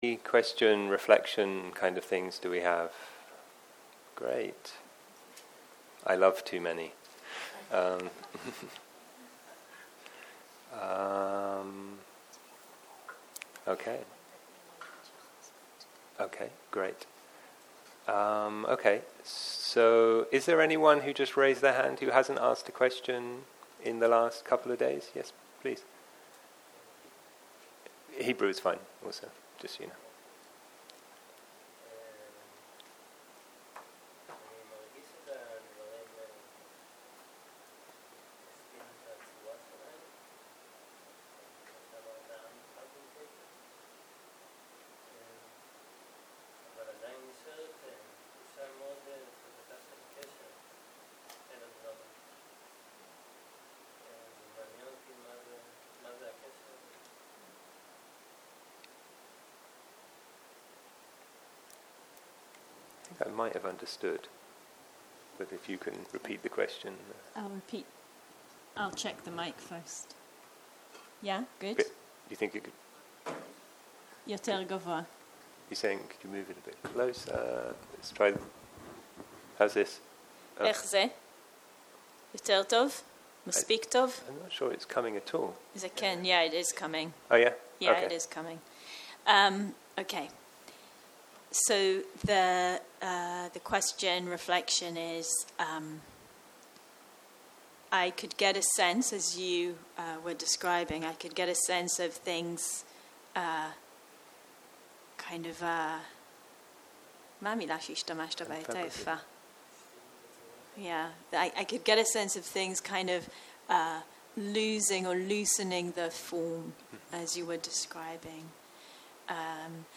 סוג ההקלטה: שאלות ותשובות שפת ההקלטה